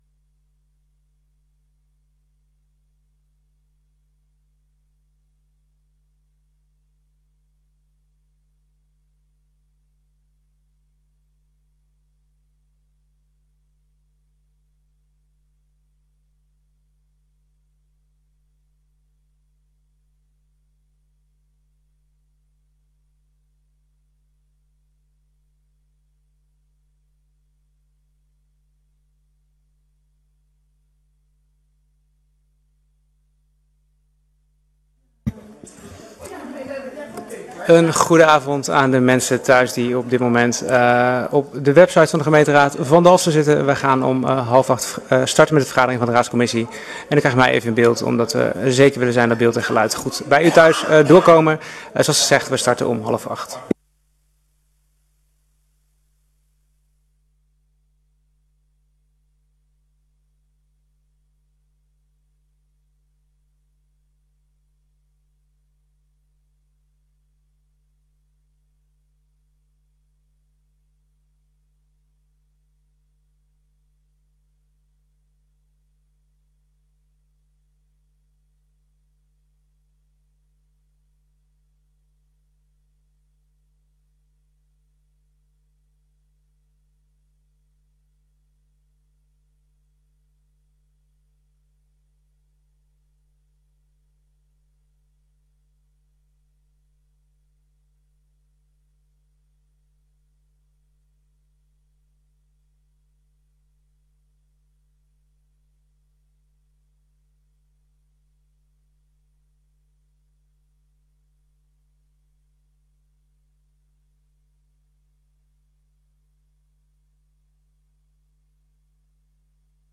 Locatie: Raadzaal Voorzitter: H.A. Zwakenberg